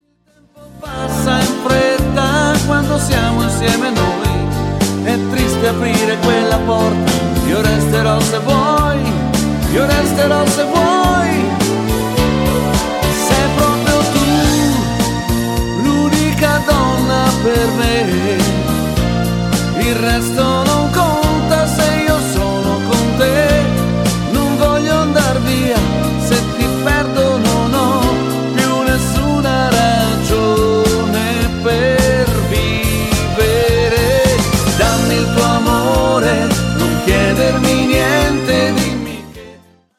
MODERATO  (3.26)